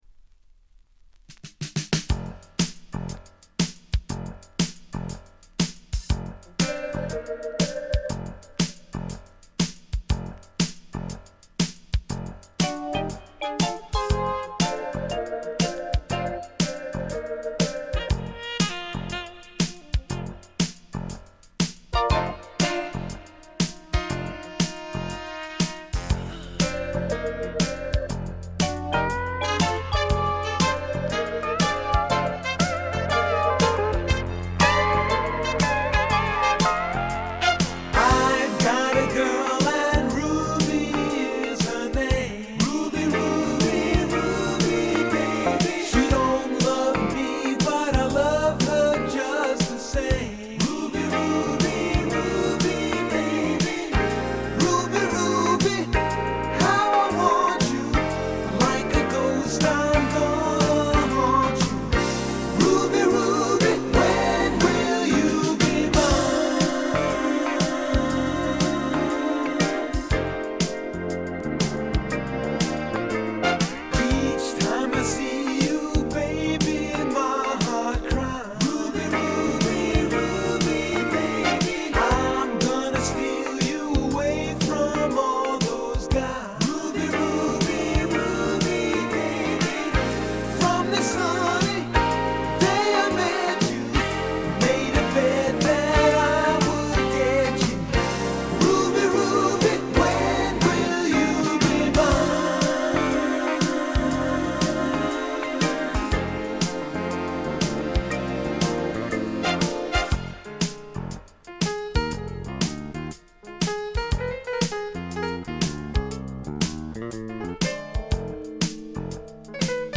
ホーム > レコード：ポップ / AOR
部分試聴ですが、ごくわずかなノイズ感のみ。
試聴曲は現品からの取り込み音源です。